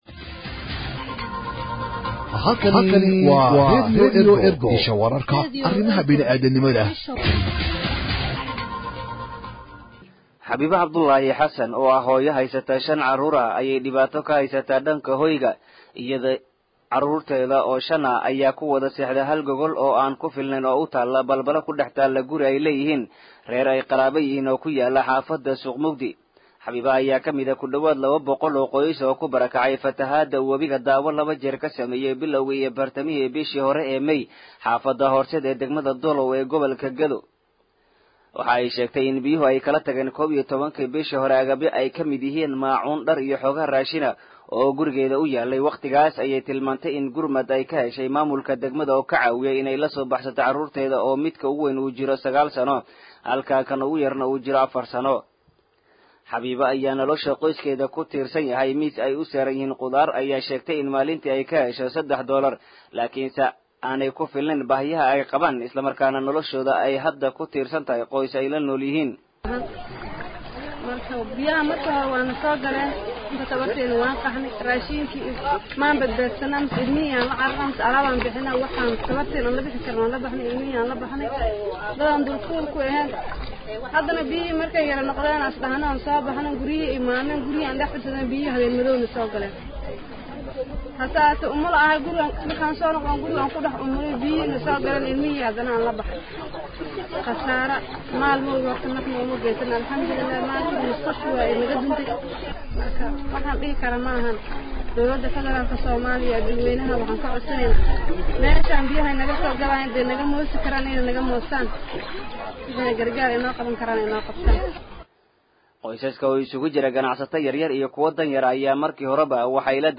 warbixin-dad-markale-kubarakacay-fatahaada-wabiga-Daawo-.mp3